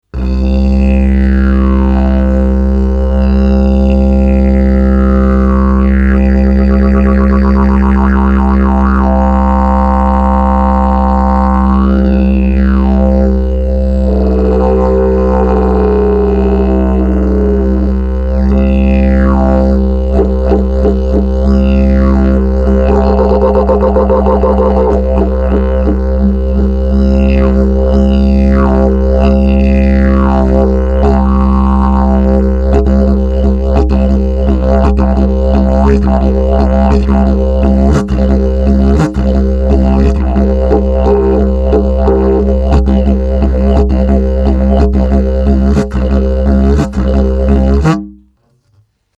Wood type: Mallee
Musical key: C
Overtones: G, D#
Categories: Bare & or Natural with some Art, Beginner Advanced, Healer, Highly Resonant and Responsive, Top Quality Instrument, Traveller